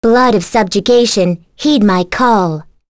Lewsia_A-Vox_Skill2_b.wav